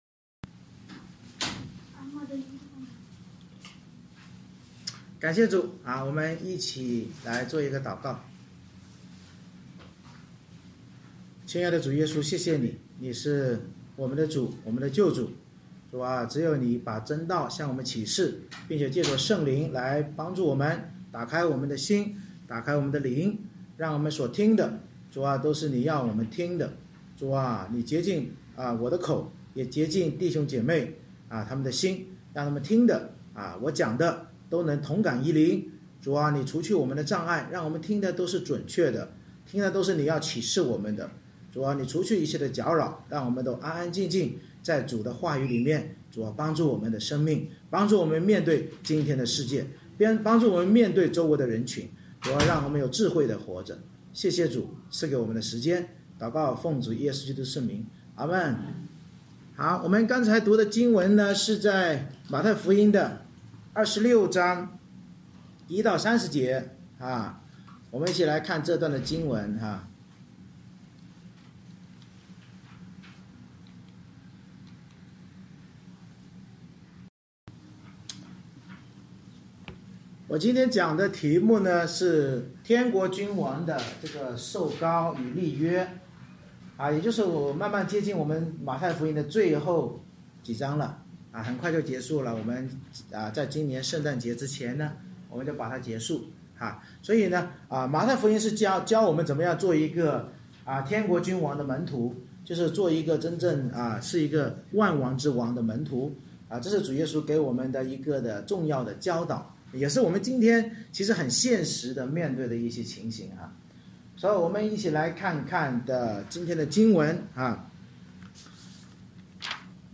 《马太福音》讲道系列 Passage: 马太福音26:1-30 Service Type: 主日崇拜 通过耶稣受难前受膏和主餐两个筵席,反省我们在面对主的十架时,是选择全然献上而与基督立约呢？